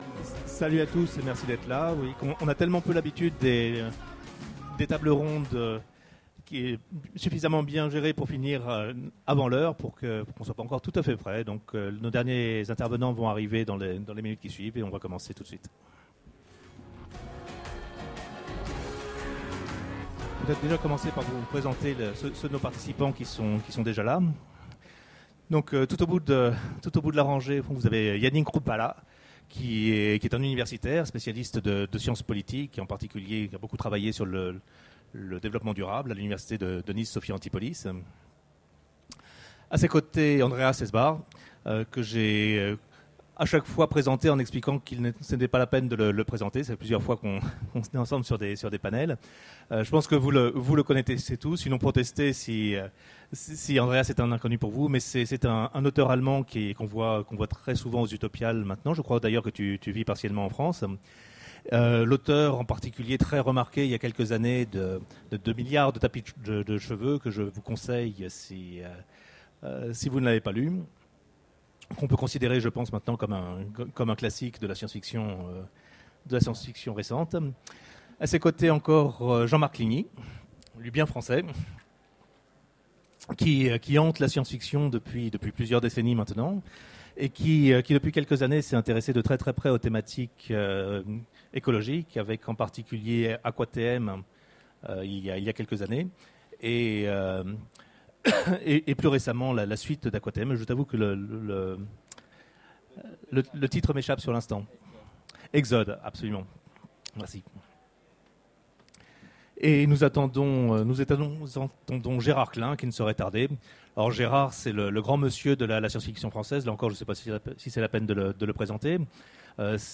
Utopiales 13 : Conférence Ressources limitées et démocraties
Conférence